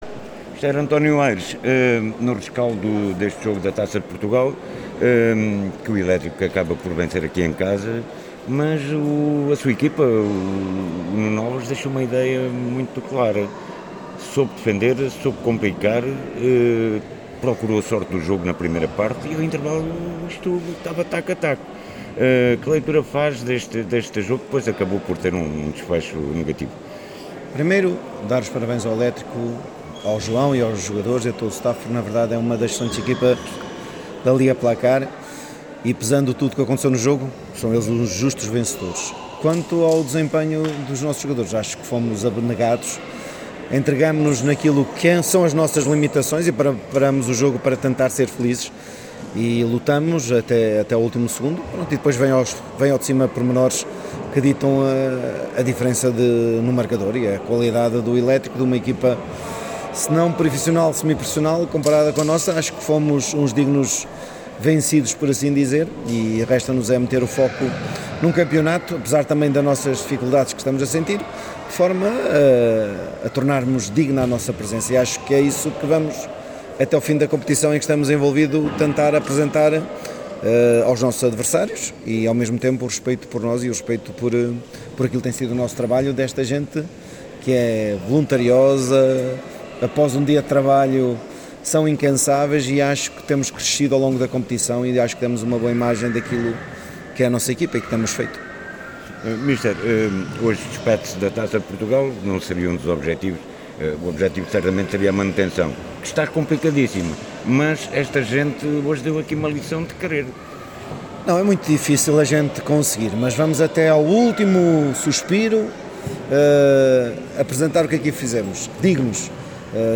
No final ouvimos os responsáveis técnicos de ambas as equipas: